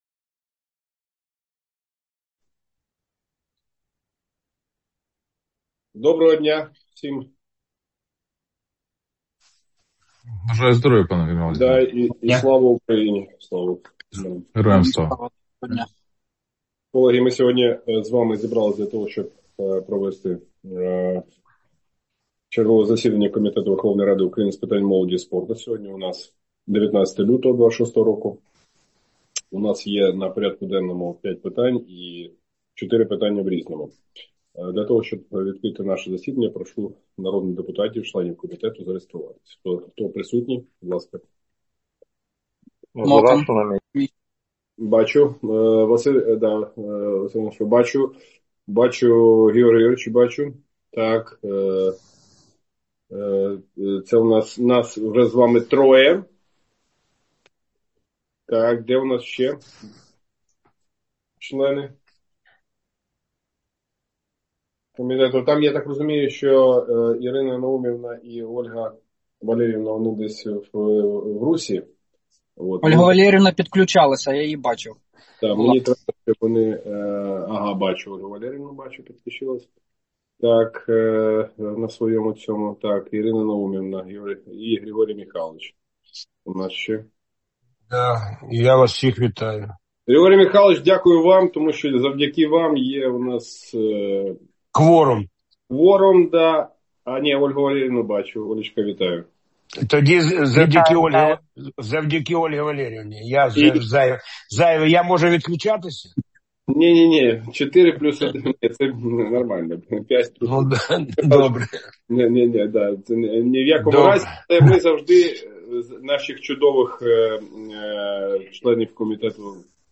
Аудіозаписи засідання Комітету у лютому 2026 року